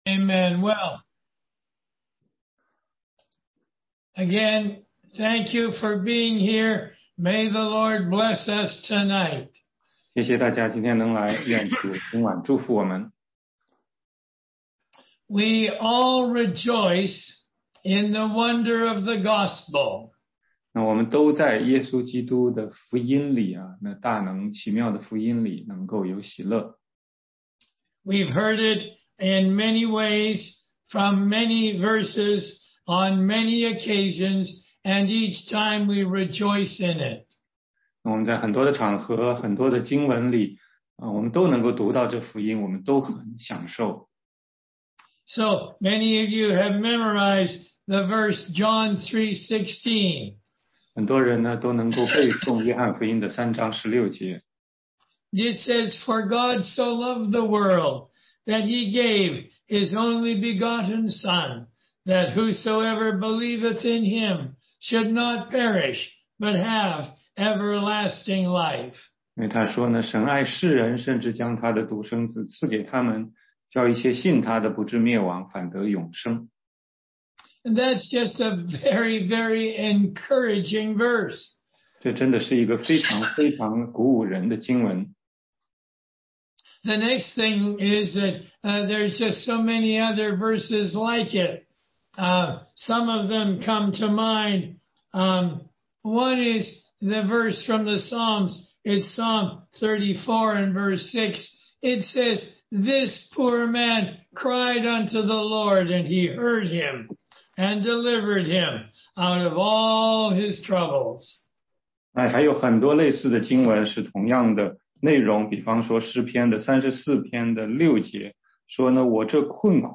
16街讲道录音 - 福音课第四十六讲